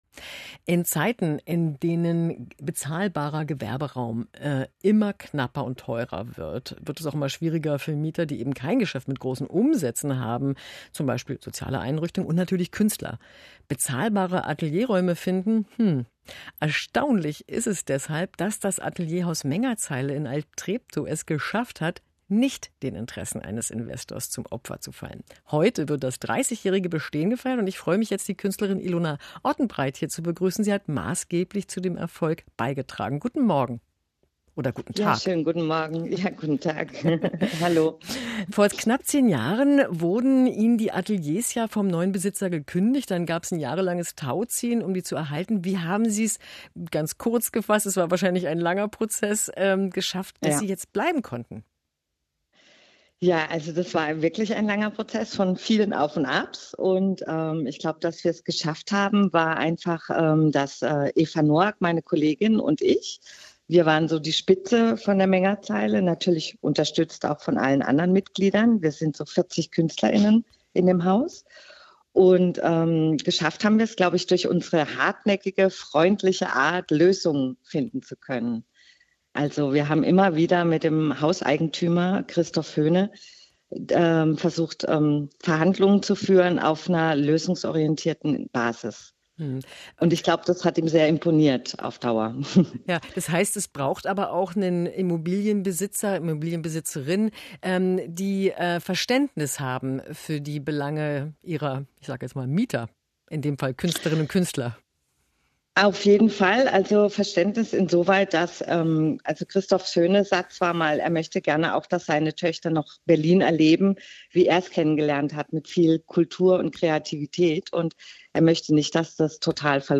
BERLIN NOCH STADT DER KÜNSTLER? 30 JAHRE ATELIERHAUS MENGERZEILE EIN GESPRÄCH MIT DEN BEIDEN KÜNSTLERINNEN